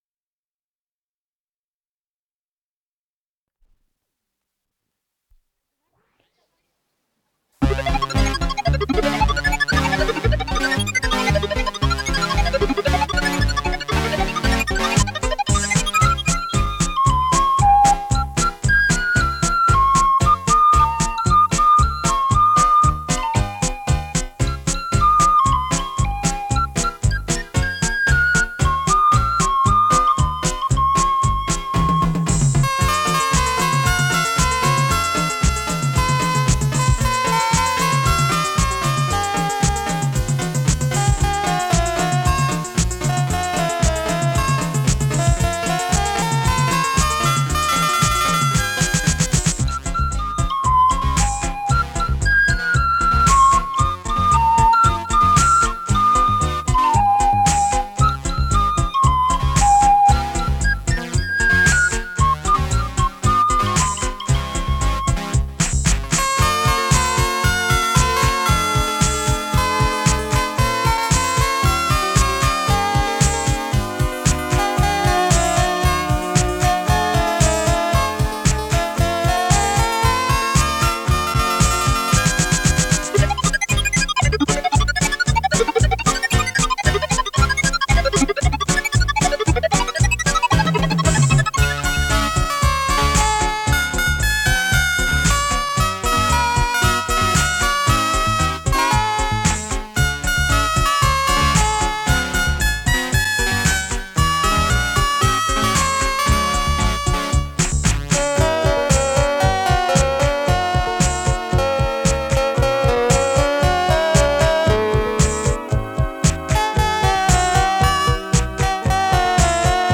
с профессиональной магнитной ленты
Скорость ленты38 см/с
ВариантМоно